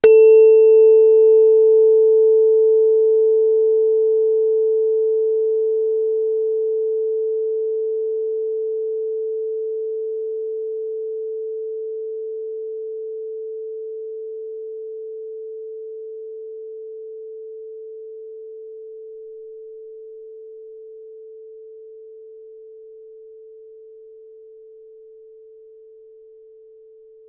Bild 8.47: (a) Stimmgabel
Tuning-fork-440Hz.ogg